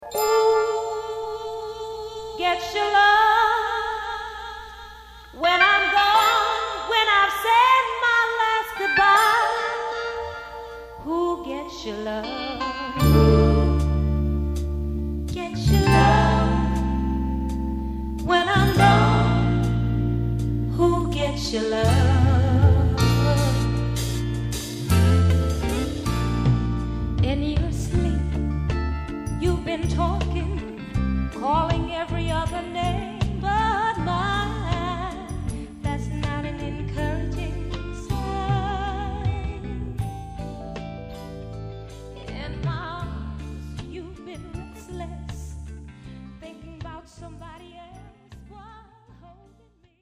そしてまた、前作同様に曲間をほとんど途切れさせぬアルバムをひとつの物語とするような演出も効果的。